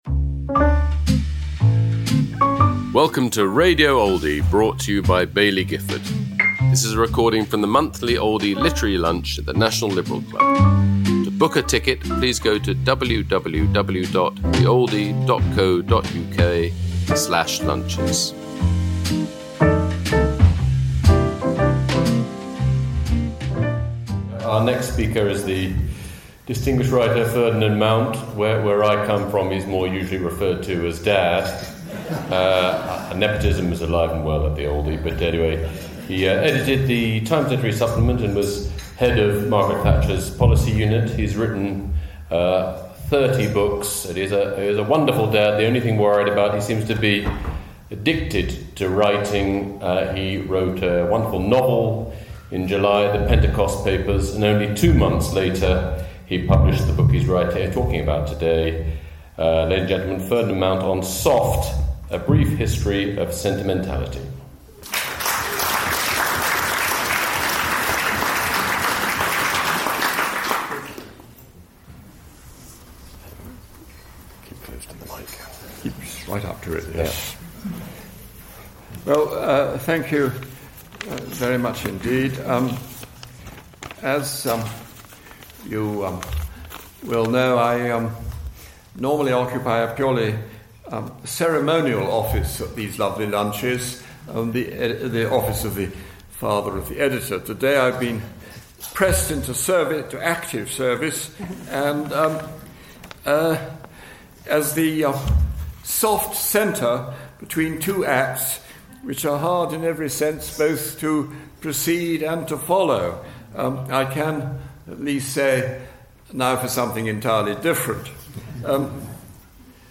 Ferdinand Mount speaking about his new book, Soft: A Brief History of Sentimentality, at the Oldie Literary Lunch, held at London’s National Liberal Club, on November 25th 2025.